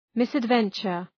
Προφορά
{,mısəd’ventʃər}